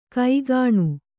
♪ kaigāṇu